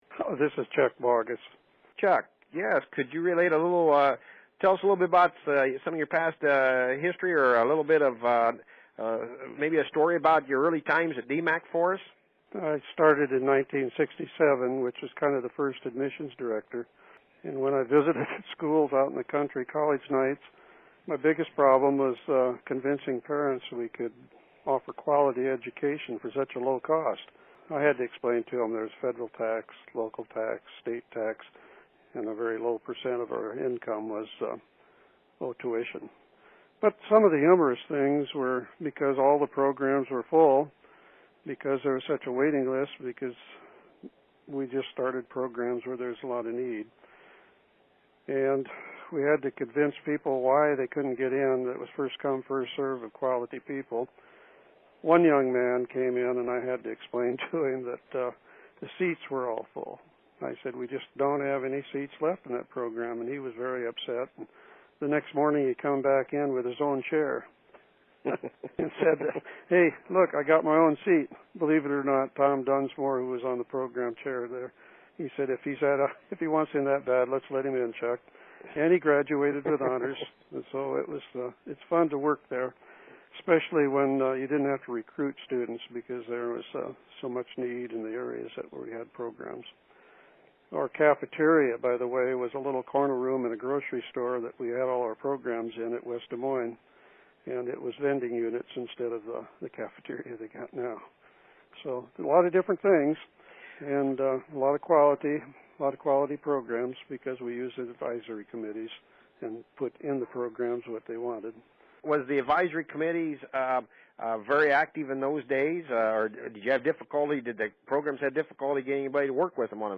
Interviews
Listen to conversations with former DMACC employees by clicking an audio player below.